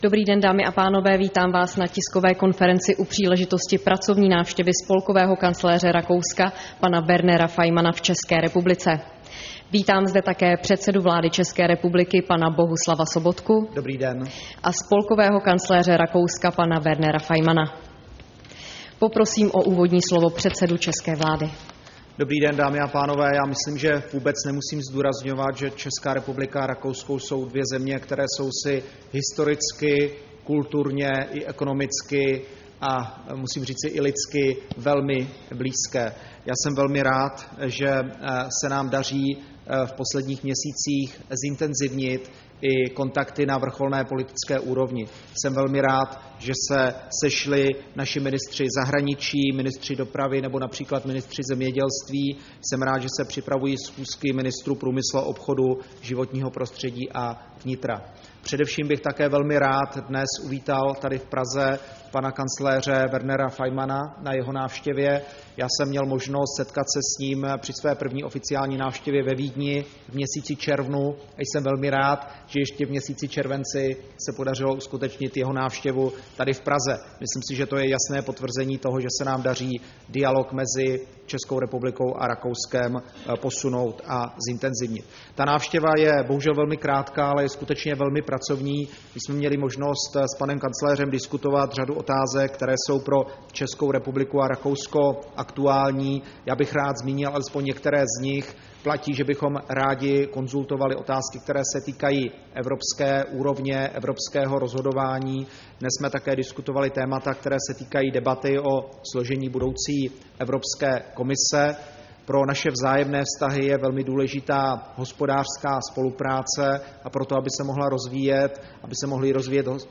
Tisková konference po setkání se spolkovým kancléřem Rakouska Wernerem Faymannem